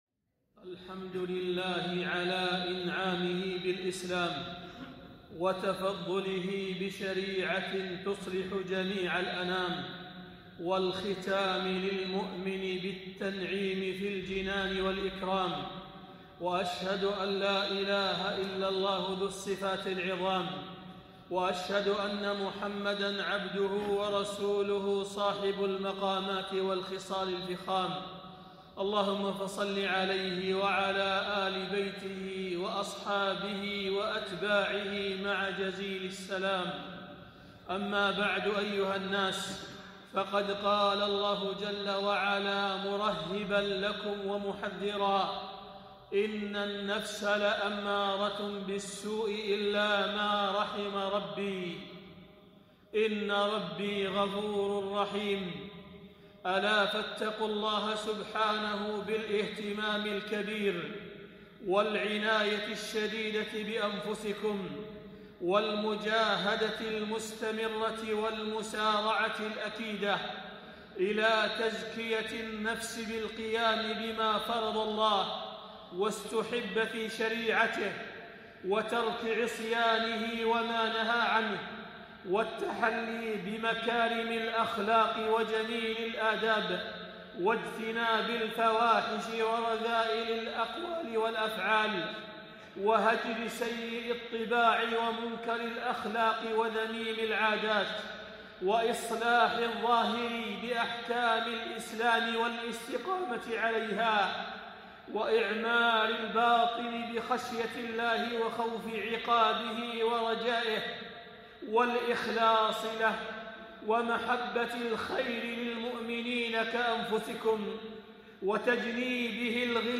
خطبة - تزكية النفس والإحسان فيما بقي من العمر